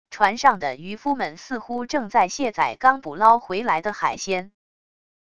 船上的渔夫们似乎正在卸载刚捕捞回来的海鲜wav音频